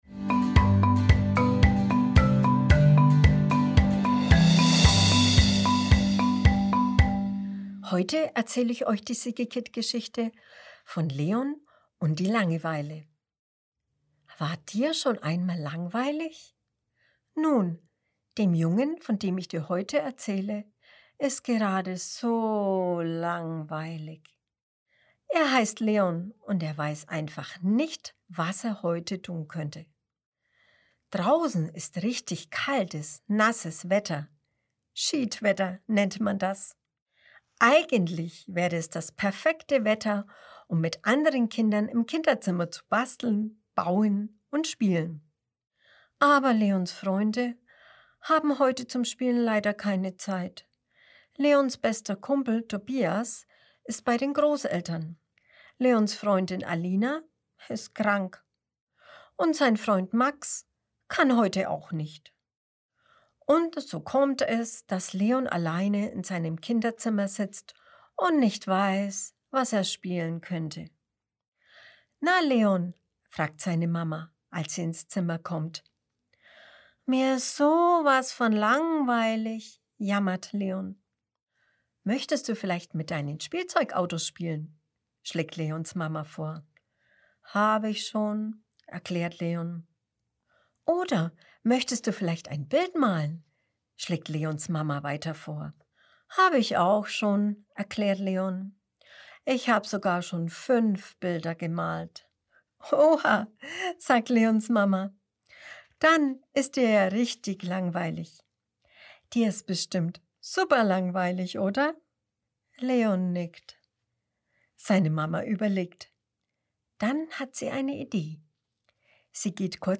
Oktober 2021 Kinderblog Vorlesegeschichten War dir schon einmal langweilig?